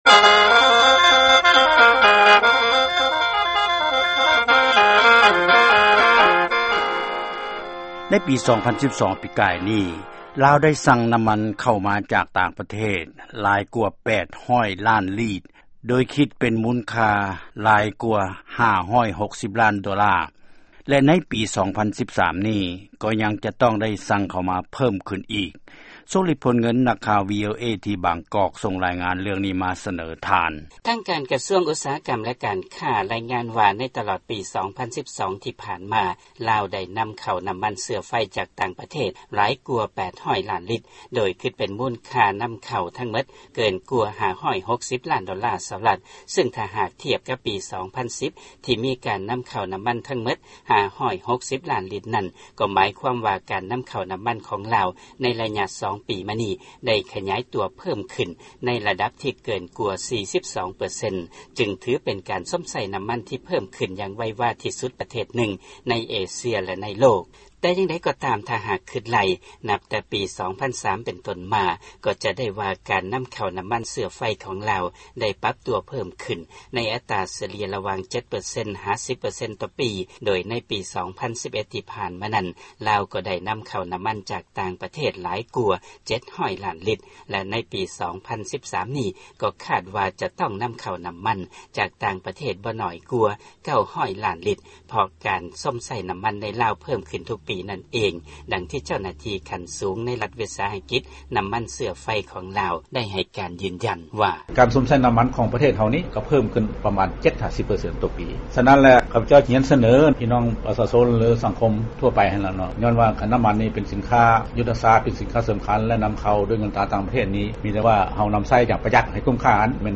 ຟັງລາຍງານການຫລຸດຜ່ອນການນໍາເຂົ້ານໍ້າມັນຂອງລາວ